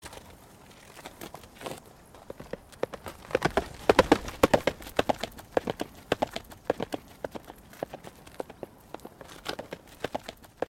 Horses Ringtone
• Animal Ringtones